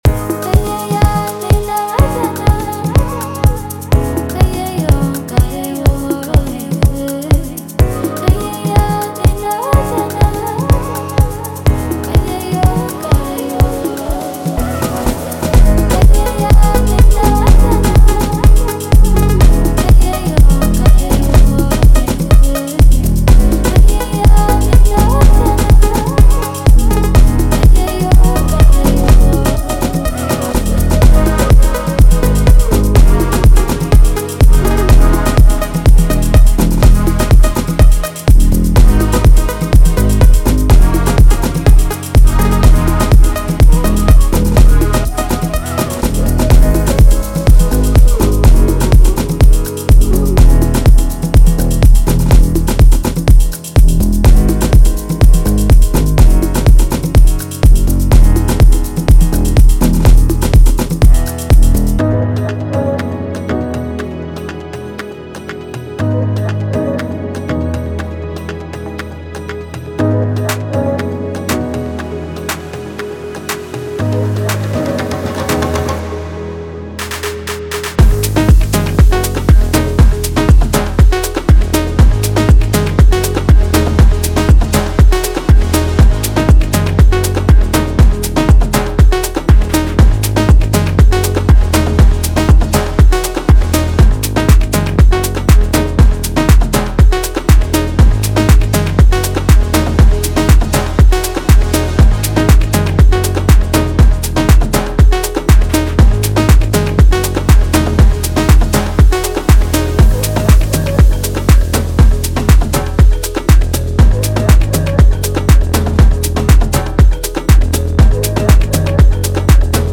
House Phonk house Tropical House
• 5 Construction Kits
• 81 Drum Loops
• 12 Bass Loops
• 18 Vocal Chops